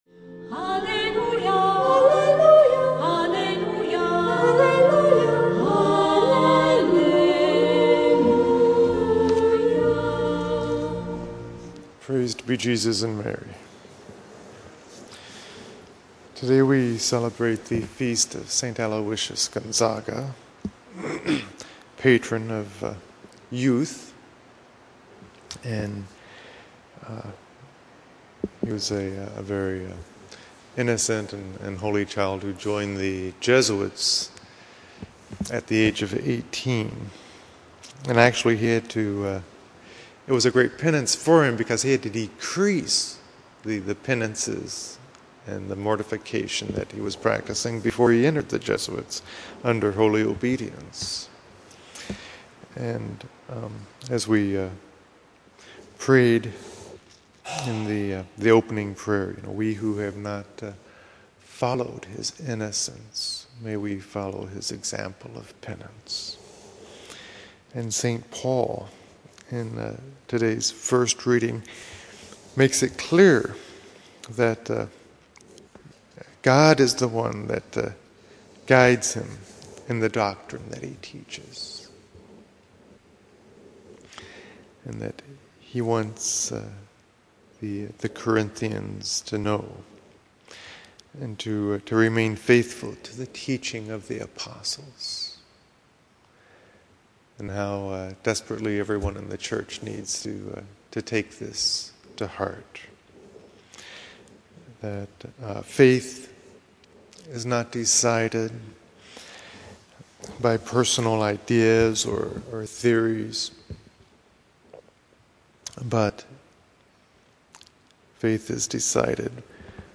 Homilies #98 - Thursday Homily (3min) >>> Play Ave Maria!